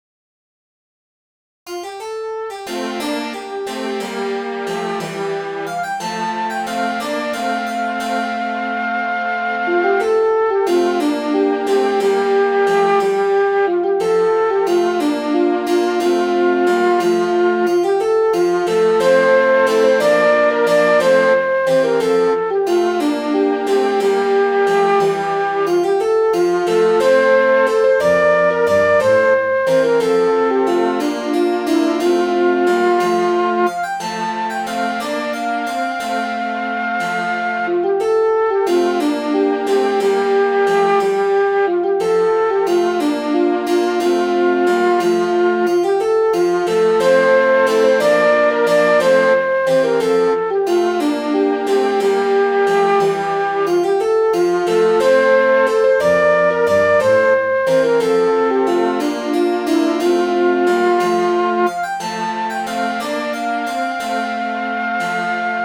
This tune is listed as an old melody in Songs of Ireland (1879).
exile.mid.ogg